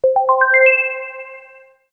正确-正确-系统提示游戏提示_爱给网_aigei_com.wav